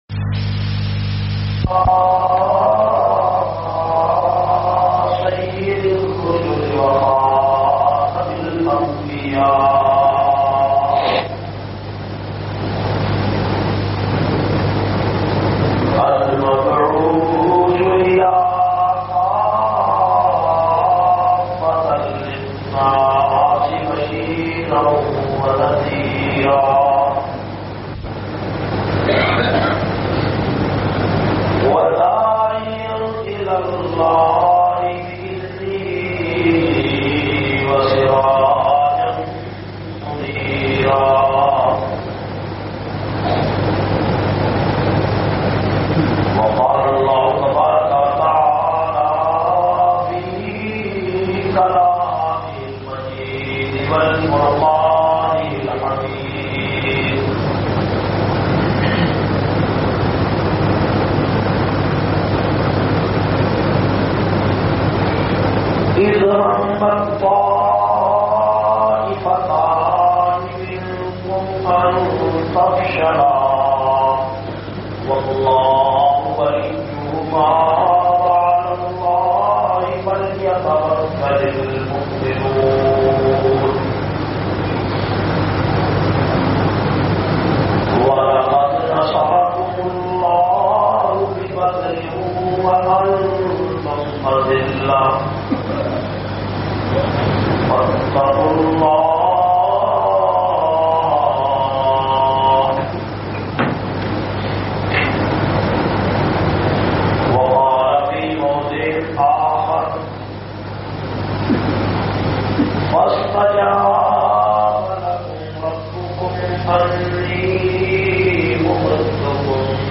448- Ghazwa e Badar khutba Jamia Masjid Muhammadia Samandri Faisalabad.mp3